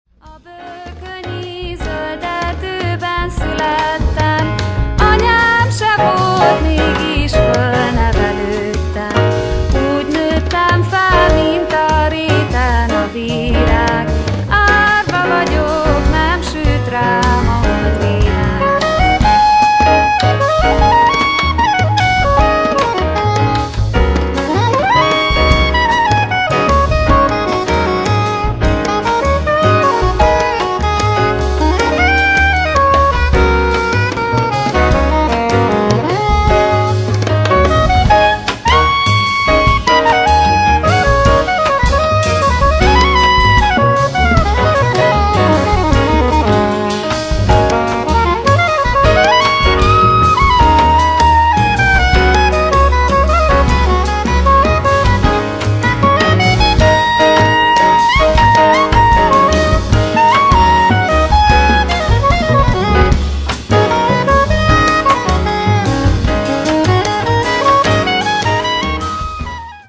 蓋を開けたら、本格派ジャズにハンガリー民謡をコラージュしたようなサウンドが展開。